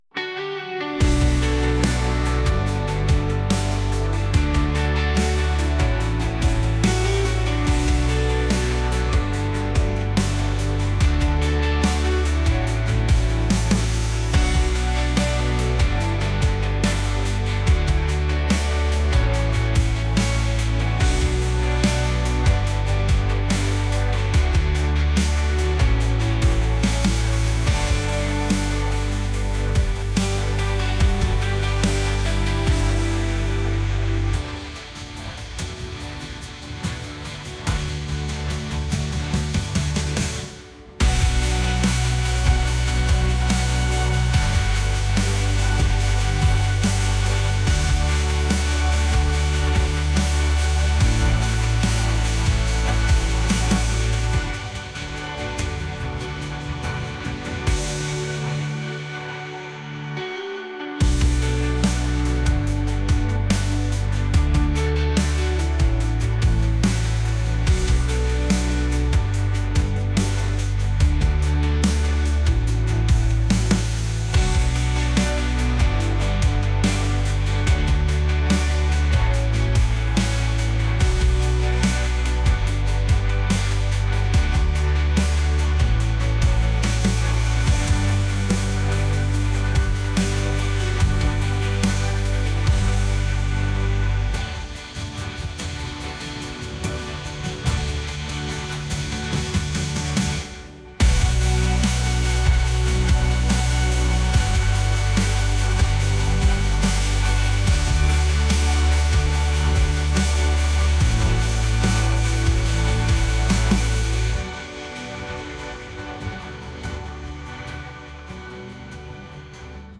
pop | rock | indie